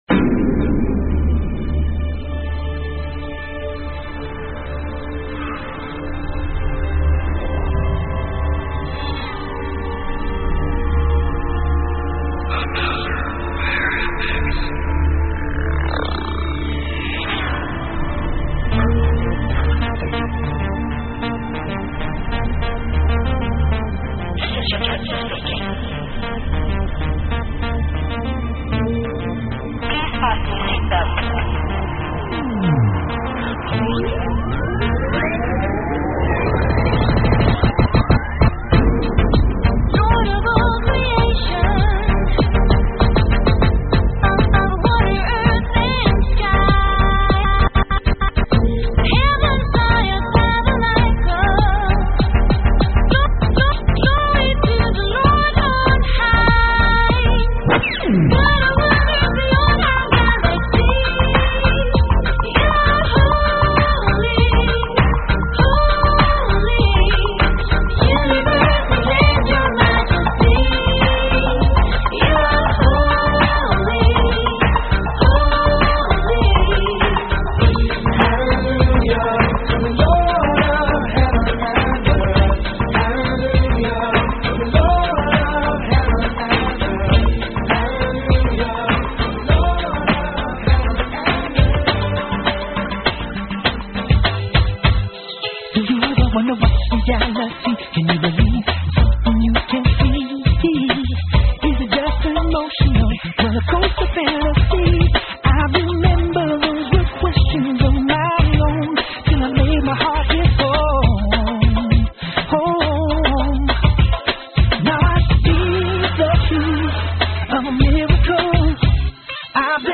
mix
Techno Dancehall RnB